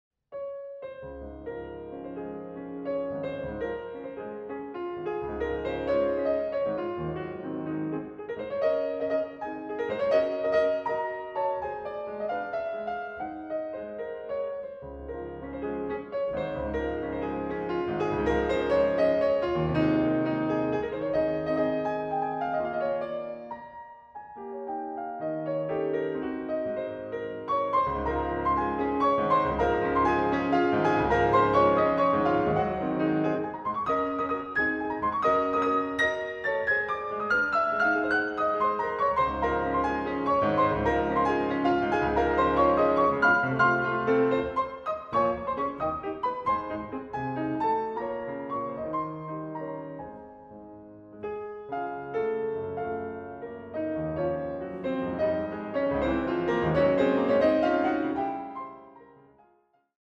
Genre: Piano music
Instrumentation: piano